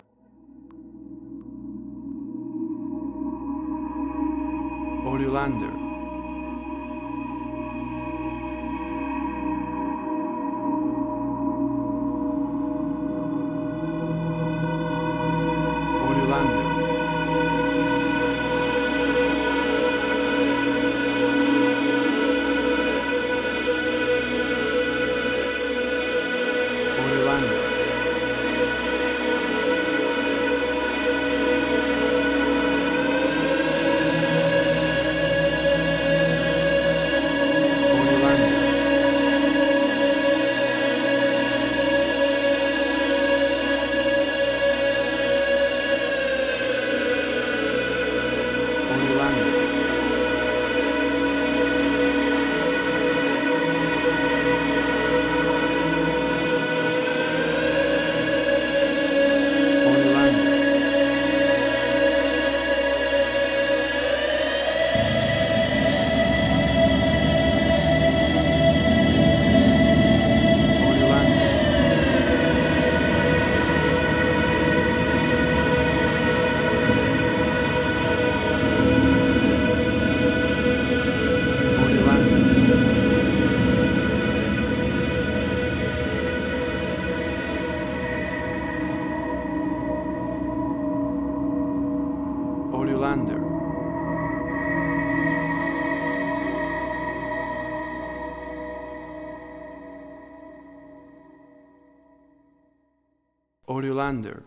Modern Film Noir.